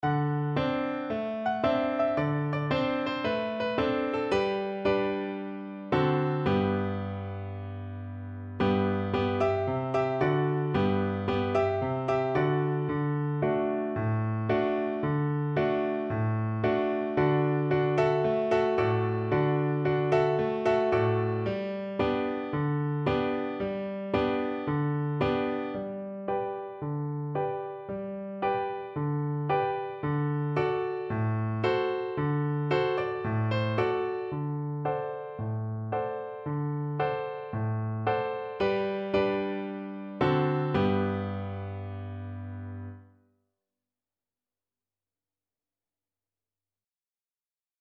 Play (or use space bar on your keyboard) Pause Music Playalong - Piano Accompaniment Playalong Band Accompaniment not yet available reset tempo print settings full screen
Cheerfully! =c.112
4/4 (View more 4/4 Music)
G major (Sounding Pitch) (View more G major Music for Flute )